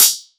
020 DnBHatD-06.wav